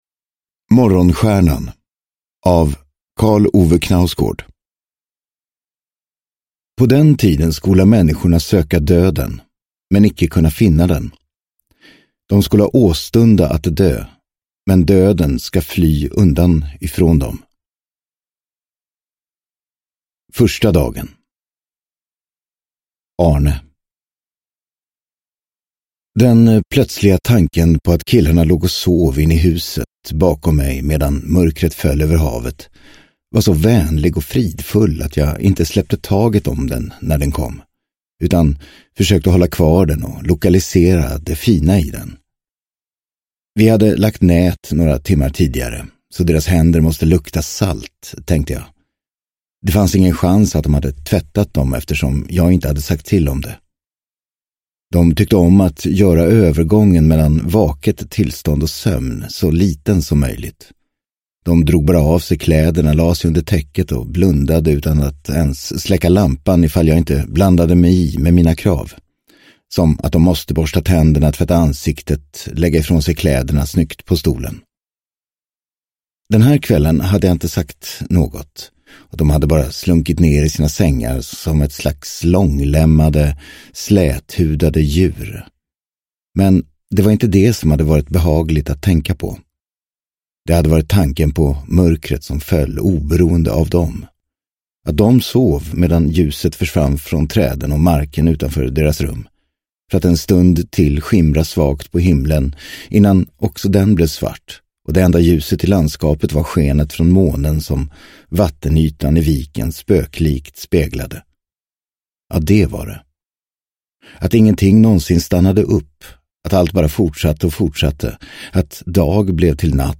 Morgonstjärnan – Ljudbok – Laddas ner